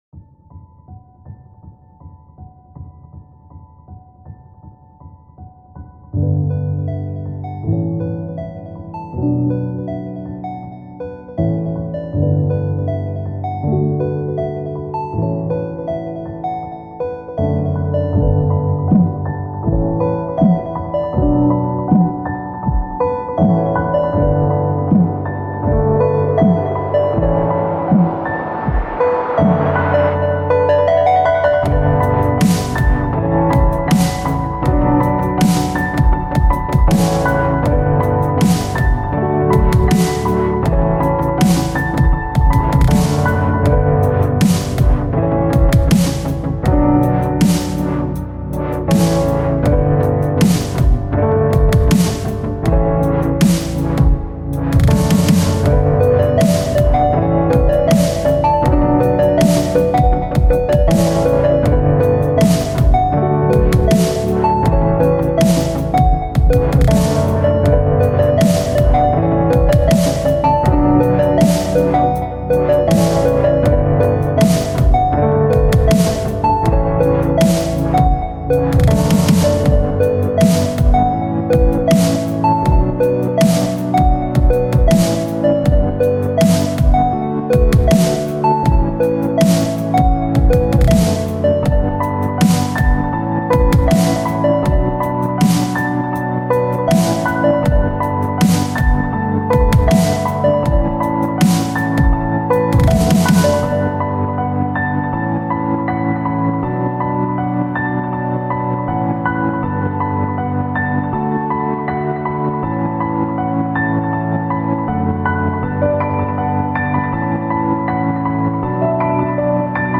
это атмосферная композиция в жанре эмбиент
мягкими синтезаторными слоями и нежными мелодиями